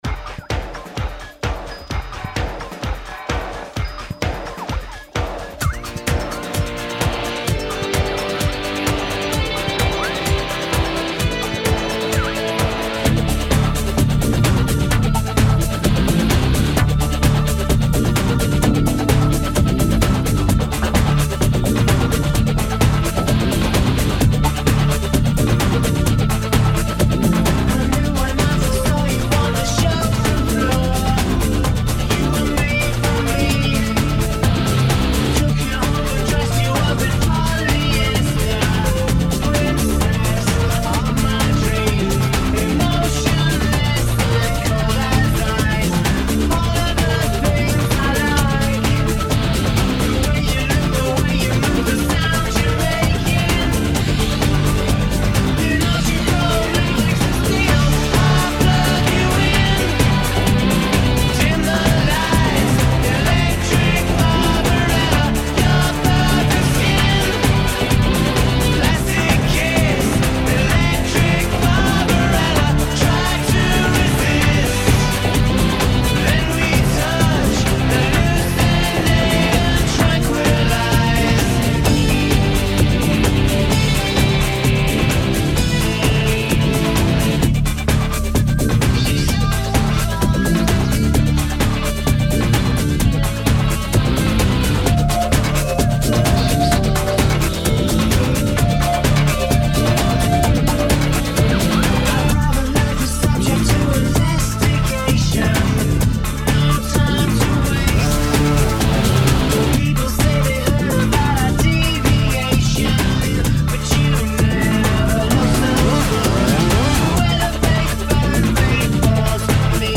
Very nice and relaxing.